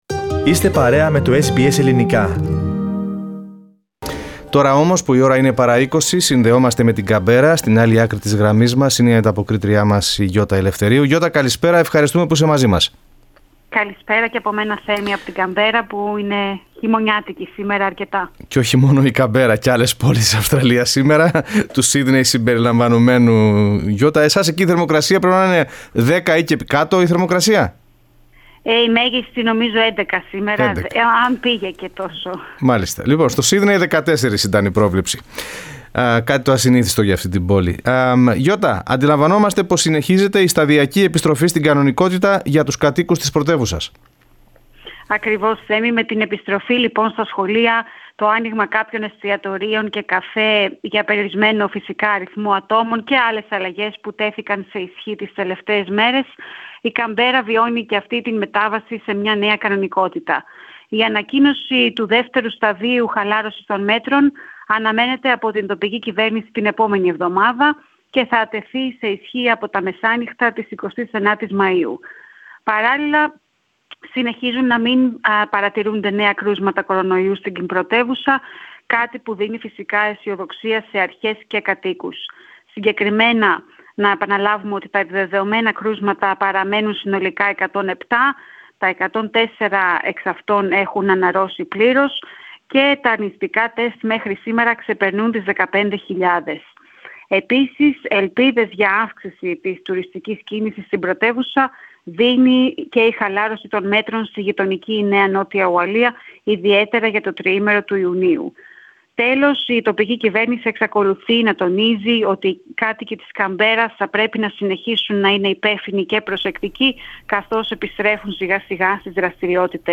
ανταπόκριση